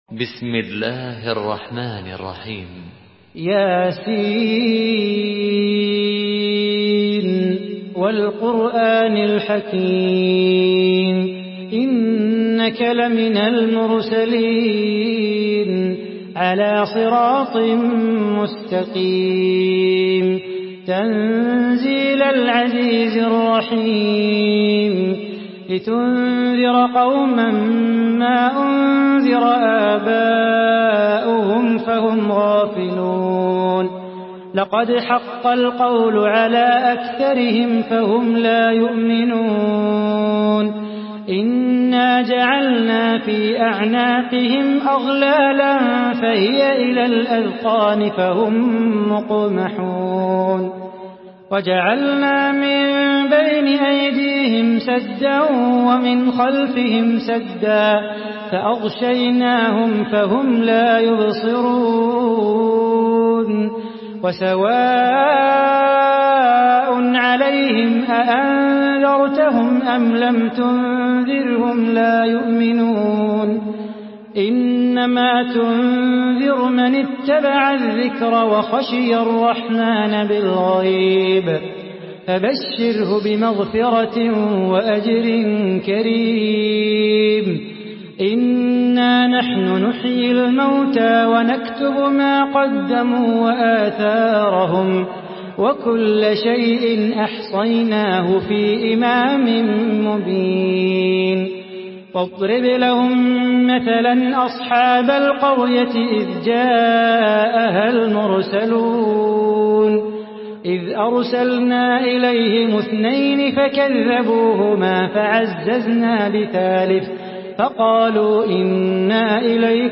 Surah Yasin MP3 in the Voice of Salah Bukhatir in Hafs Narration
Murattal